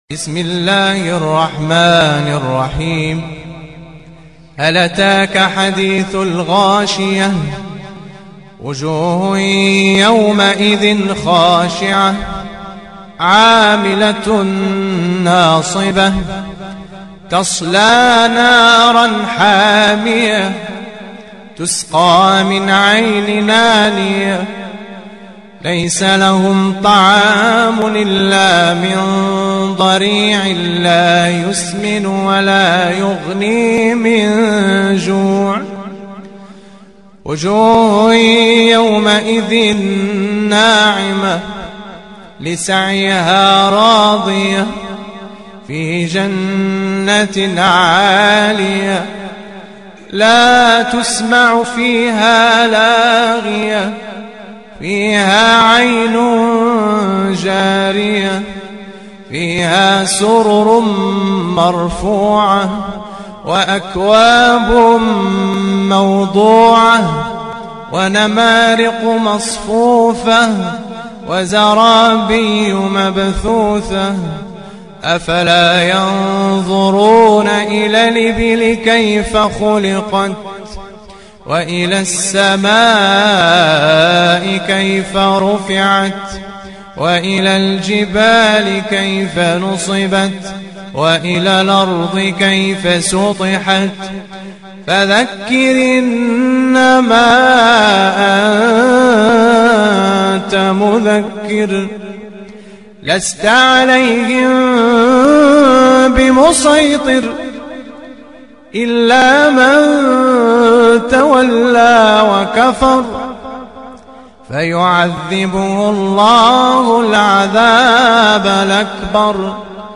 تلاوة